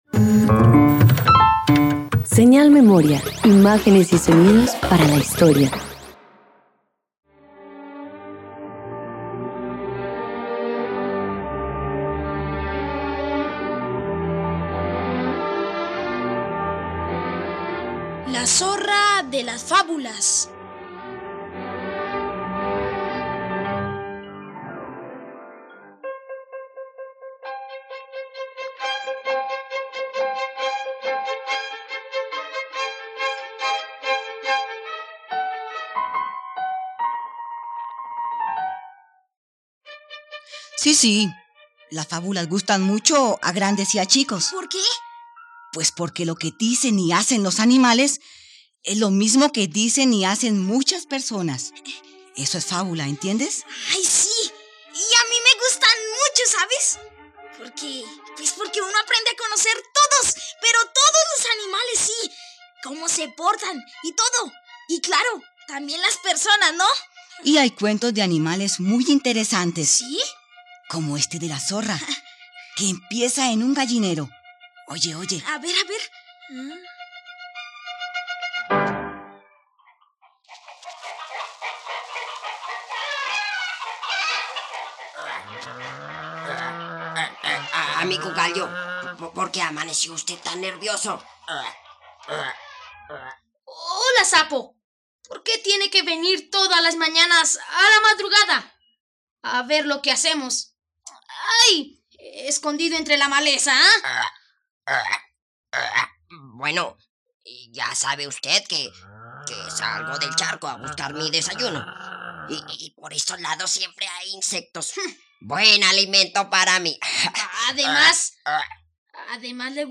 Una obra original del pionero del radioteatro infantil colombiano, José Agustín Pulido Téllez.